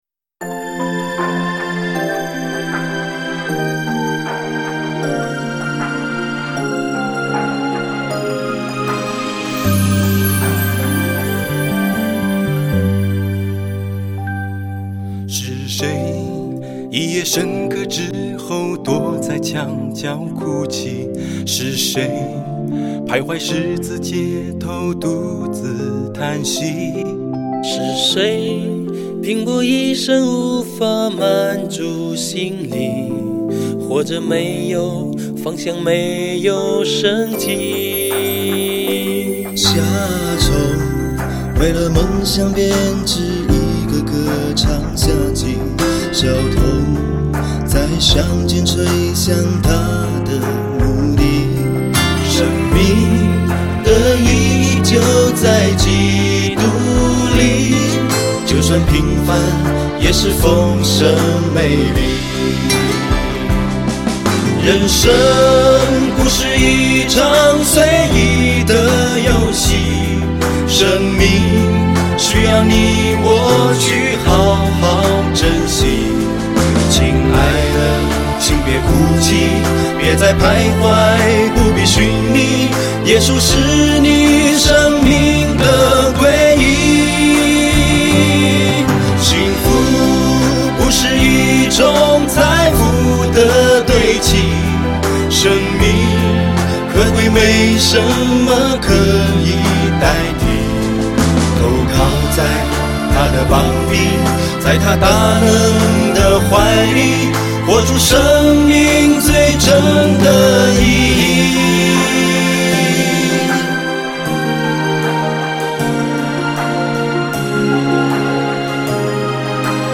HAKA祷告敬拜MP3 启示性祷告： 持续祷告： 祈求神的旨意成就在我们身上，启示性恩膏临到，明白身份，听到呼召，进入命定！